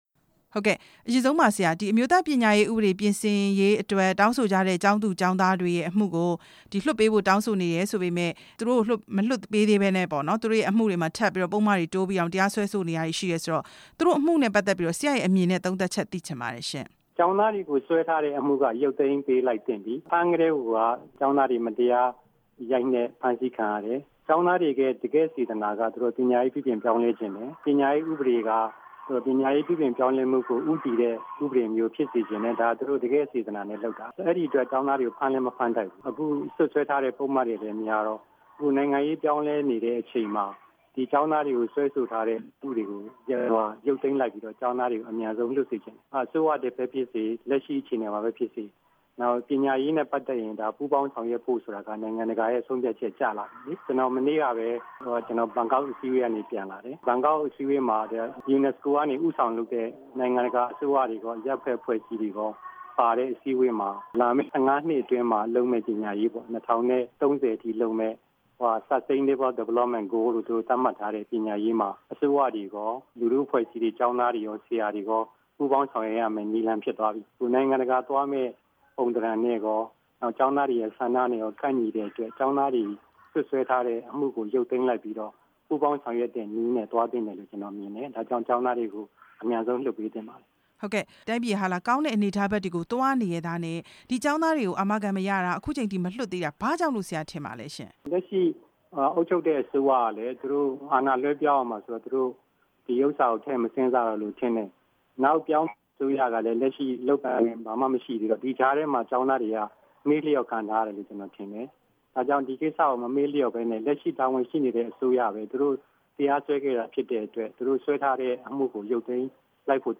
အစိုးရသစ်နဲ့ NNER အဖွဲ့ပူးပေါင်း လုပ်ဆောင်သွားမယ့်အကြောင်း မေးမြန်းချက်